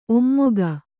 ♪ ommoga